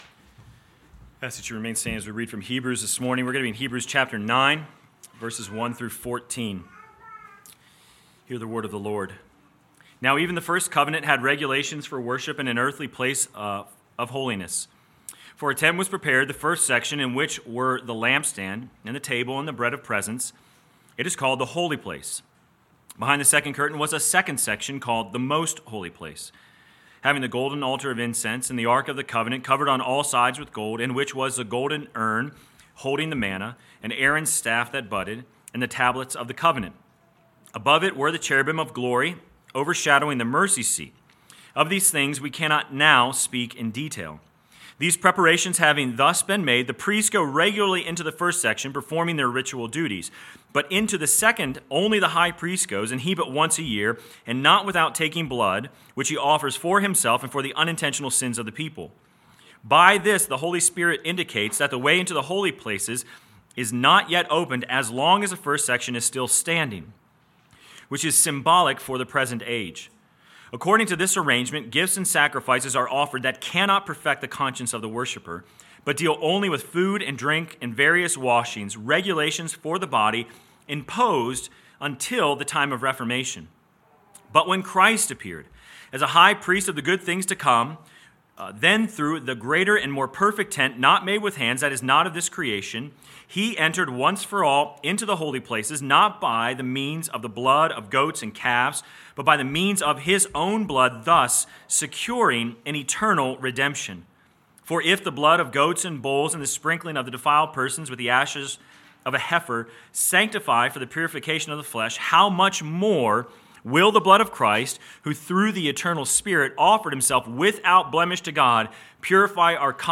Sermon Text: Hebrews 9:1-14 First Reading: Leviticus 16:1-17 Second Reading: Romans 6:1-14